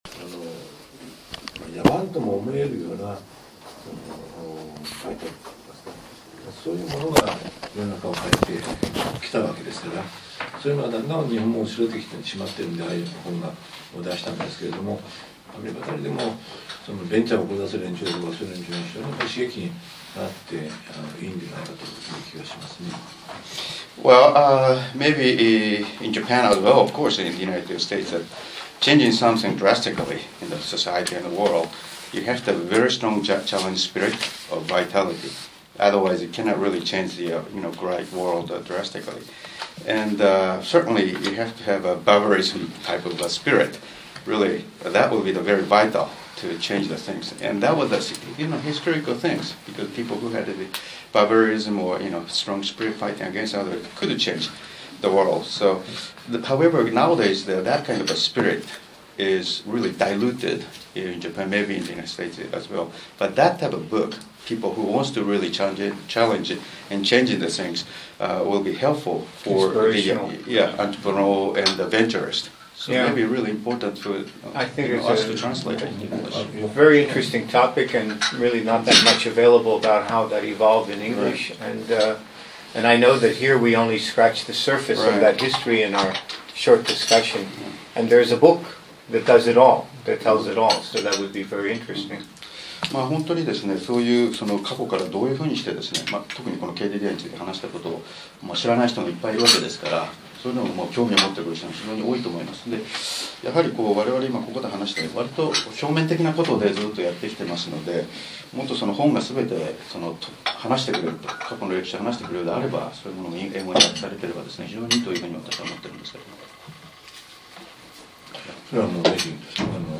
Oral history interview with Kazuo Inamori